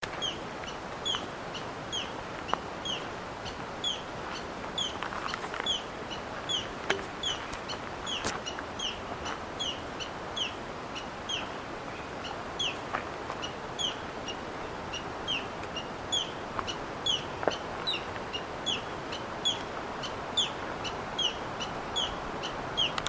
Carpintero Pitío (Colaptes pitius)
121016_006-Carpintero-Pitio.mp3
Fase de la vida: Adulto
Localidad o área protegida: Parque Nacional Conquillio
Condición: Silvestre
Certeza: Observada, Vocalización Grabada